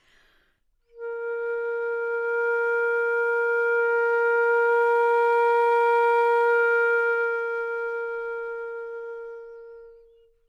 长笛单音（吹得不好） " 长笛 Asharp4 baddynamics
Tag: 好声音 单注 多样本 Asharp4 纽曼-U87 长笛